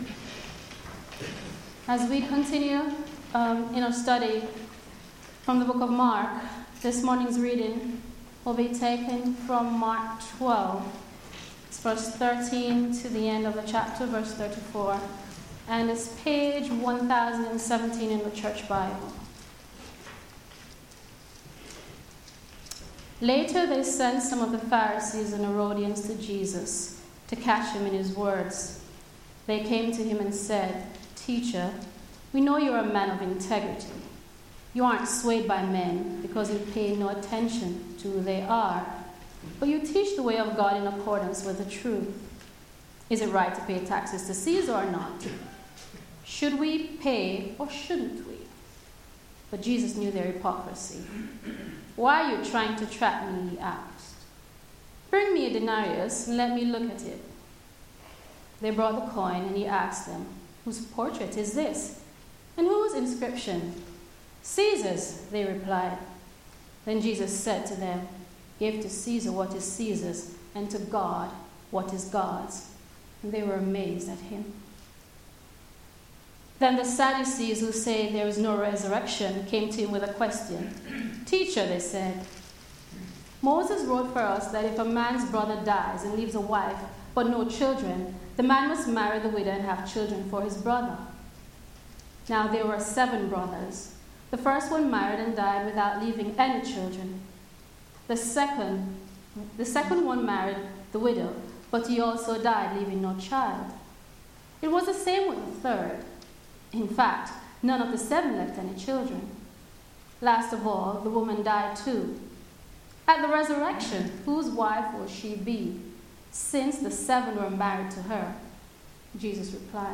Media for Sunday Service
Testing Jesus Sermon